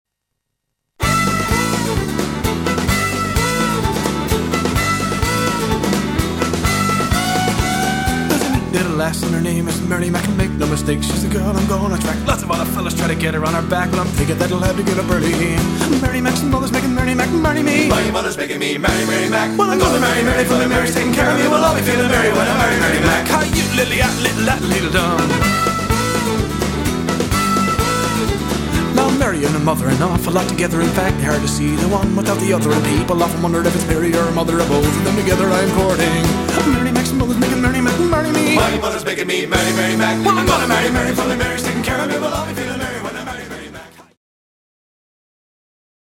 Country & Western Hits